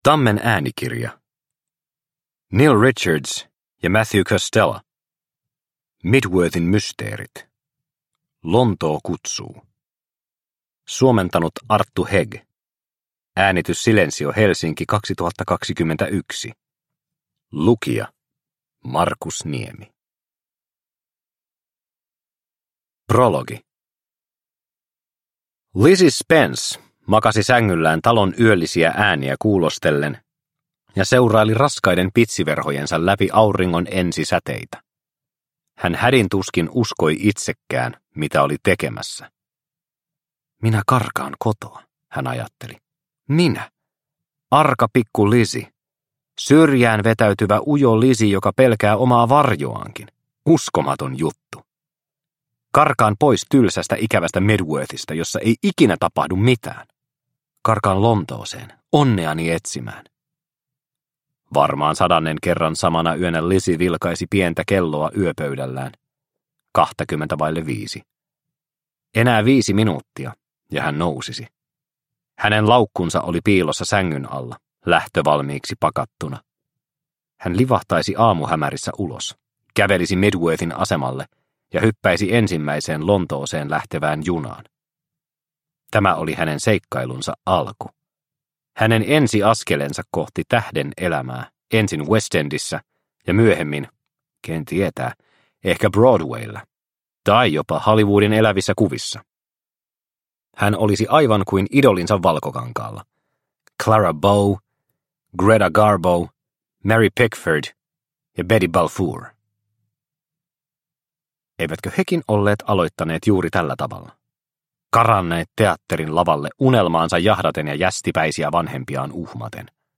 Mydworthin mysteerit: Lontoo kutsuu – Ljudbok – Laddas ner